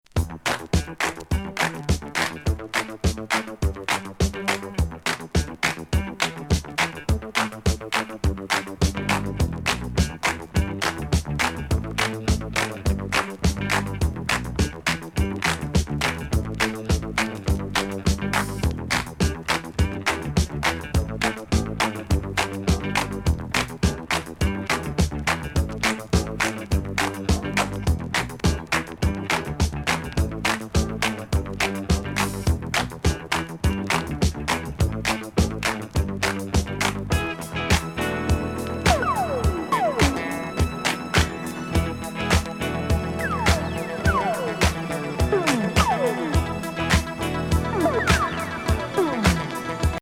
ミッド・テンポに浮遊感満点のドリーミンシンセ
夢見心地な好ディスコ！